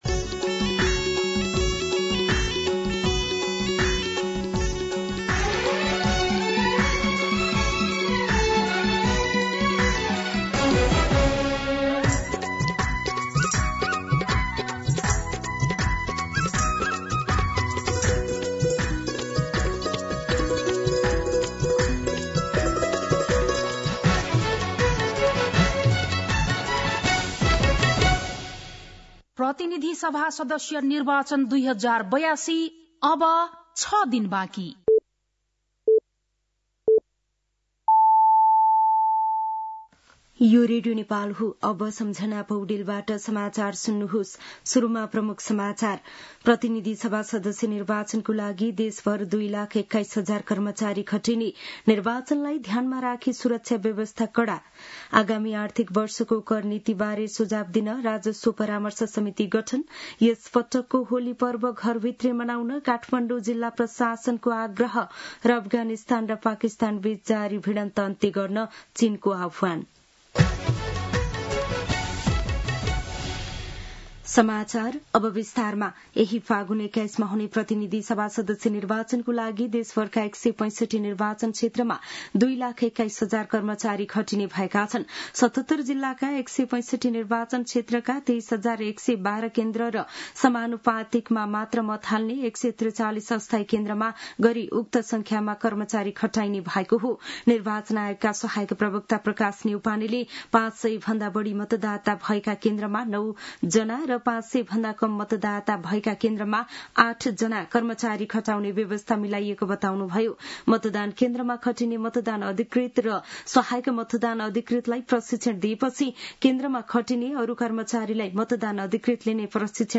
दिउँसो ३ बजेको नेपाली समाचार : १५ फागुन , २०८२